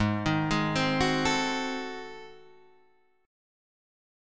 Ab7b9 chord